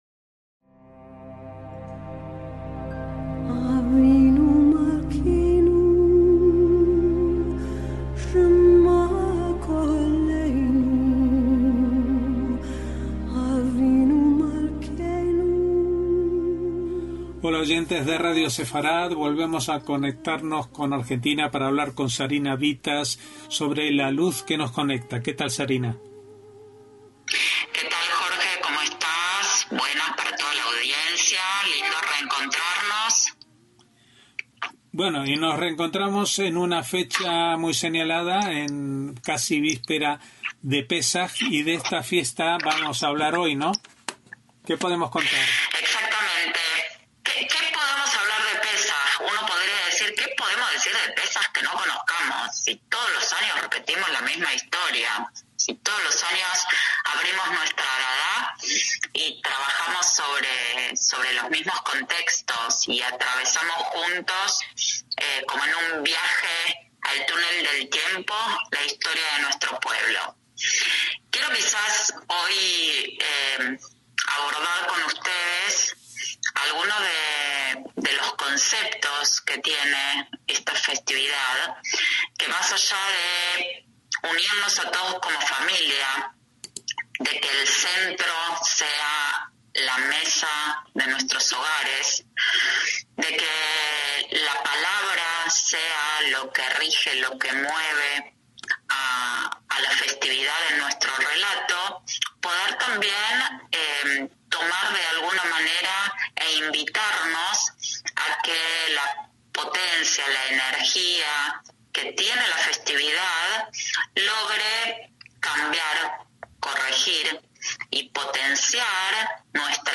a pesar de la baja calidad del sonido por problemas técnicos a la hora de grabar, por lo que pedimos disculpas